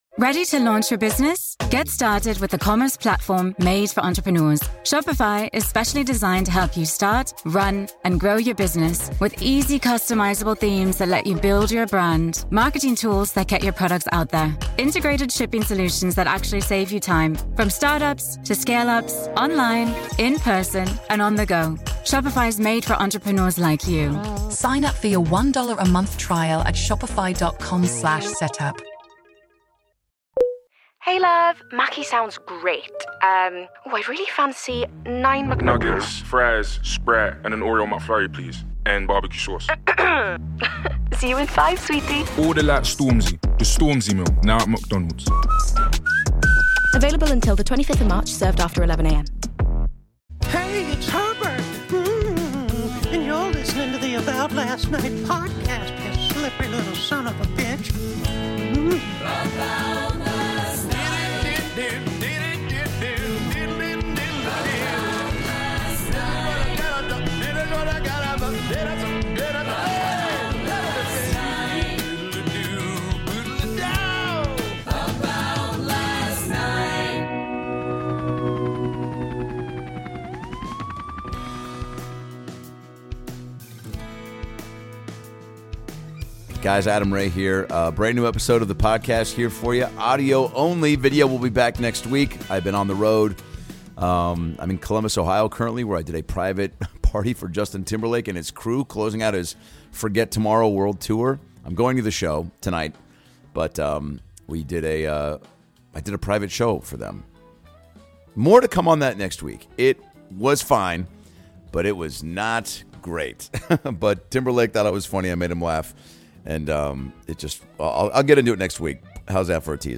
Dr. Phil LIVE! is coming to you from San Fransisco, California with a show so big we had to release it in two parts!